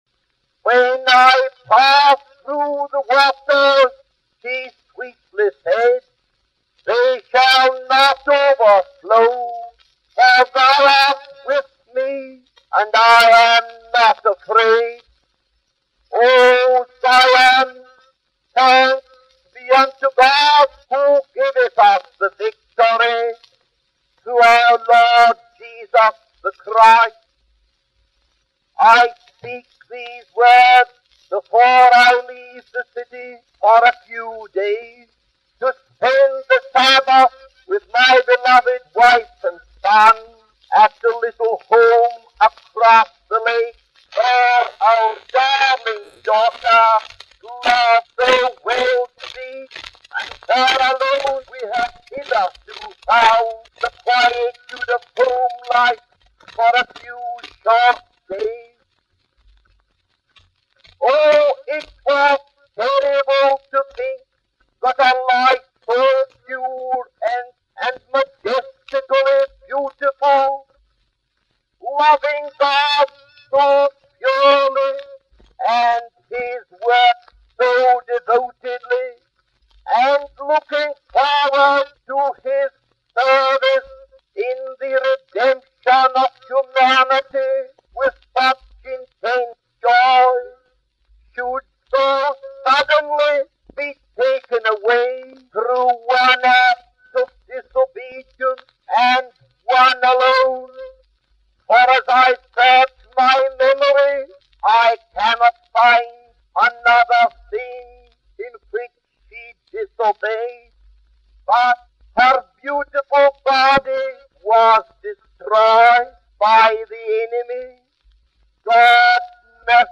The sermon reflects on the sudden and tragic death of Esther Dowie, highlighting God's mercy and the enemy's power.